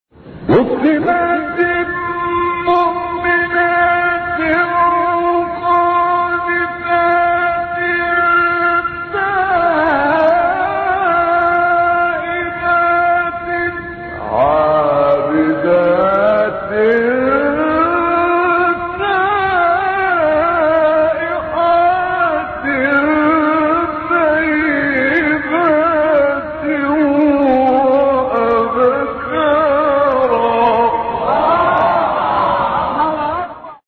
فرازهای صوتی از قاریان به‌نام مصری